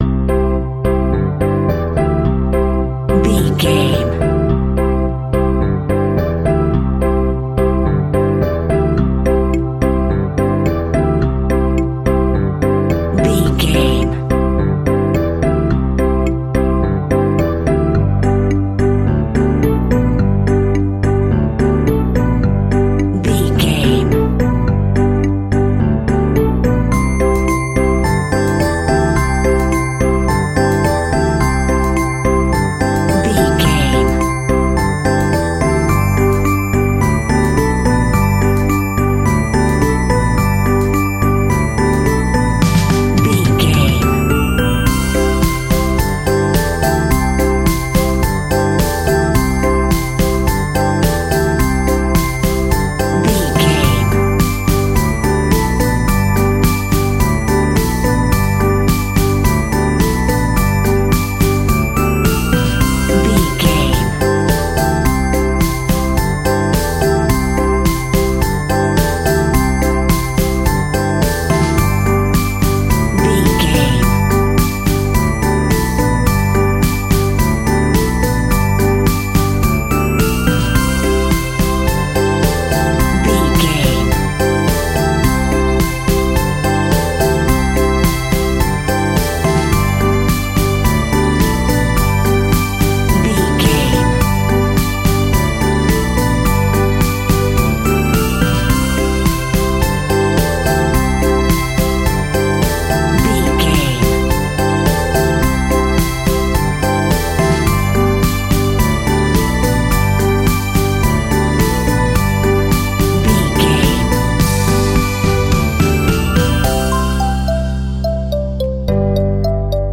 Music for Kids.
Ionian/Major
kids instrumentals
fun
childlike
cute
happy
kids piano